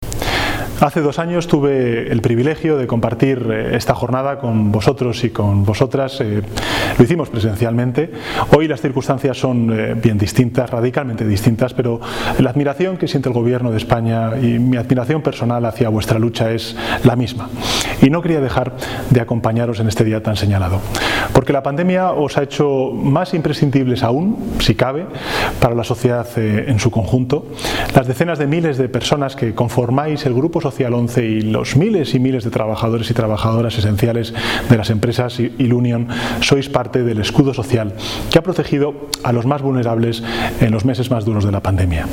Pedro Sánchez formato MP3 audio(0,80 MB), que quiso mandar un mensaje de felicitación, rememorando el encuentro que vivió en persona con la ONCE en el pabellón de IFEMA de Madrid hace dos años para celebrar su 80 aniversario, ante más de 5000 personas de todo el Grupo Social ONCE.